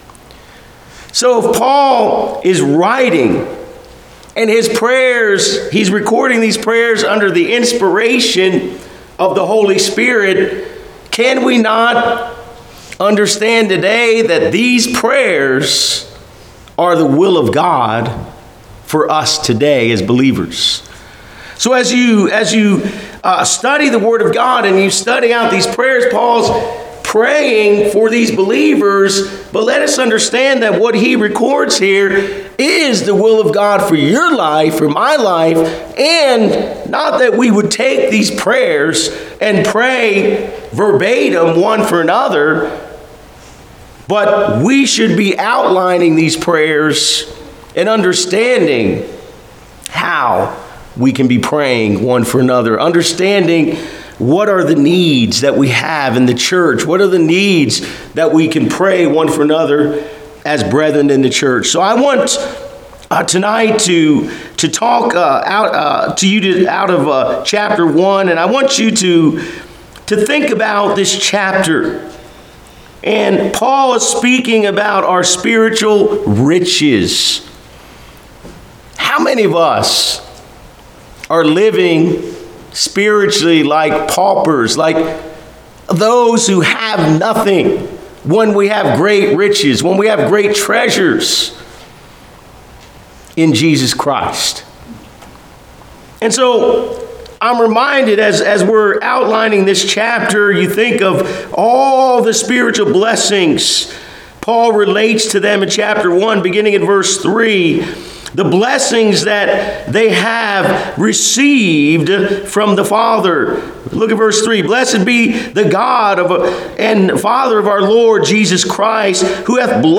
Service Type: Wednesday Evening